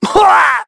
Evan-Vox_Damage_03.wav